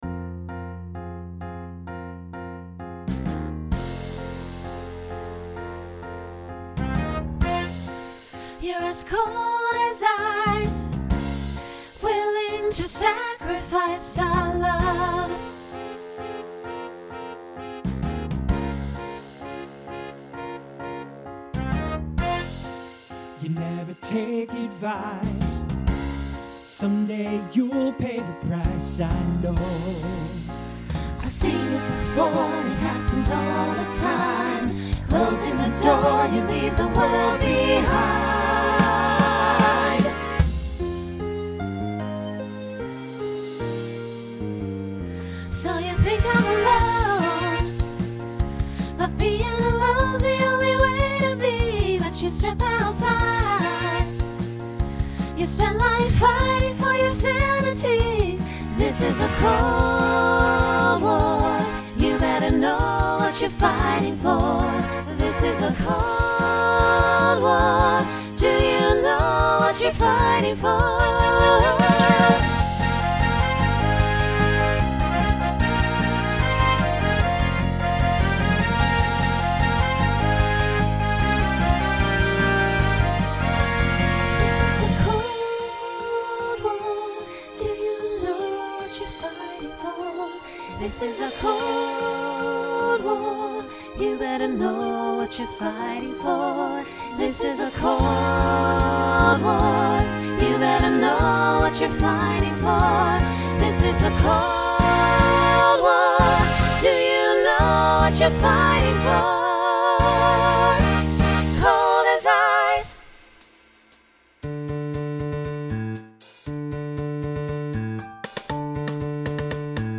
Starts SATB, then SSA, then TTB.
Pop/Dance , Rock
Voicing Mixed